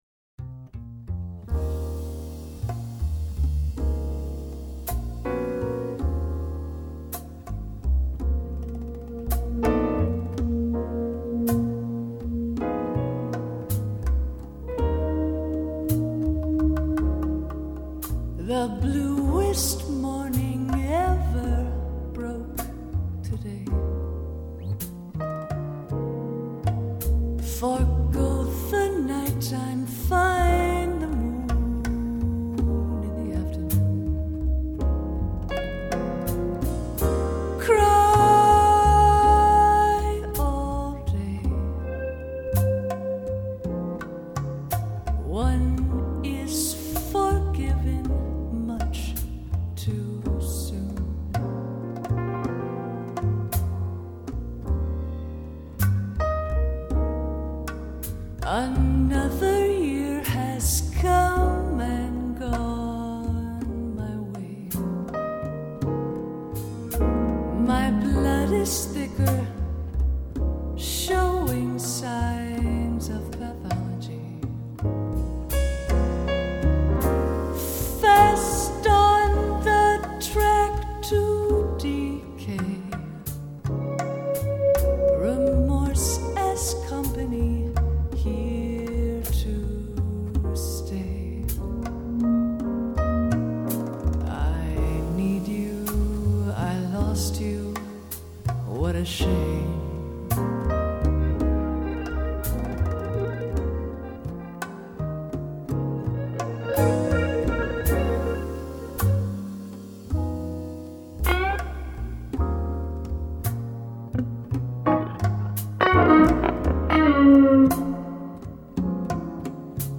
-   爵士及藍調 (299)
★ 擁有神秘低沉嗓音、自我風格最濃厚爵士女歌手，本張專輯展現她的歌唱魅力與音樂巧思！